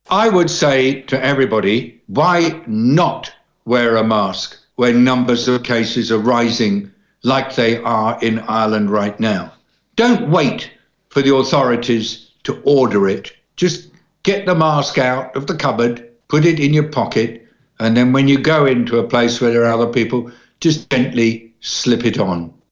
Dr David Nabarro, the W-H-O’s special envoy on Covid, says it’s time to wear face-masks again…